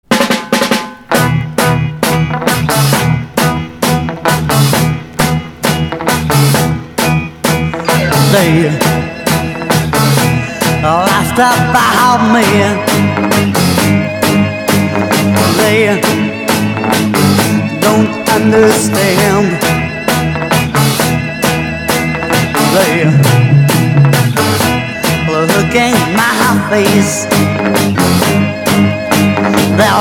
Beat garage Unique 45t retour à l'accueil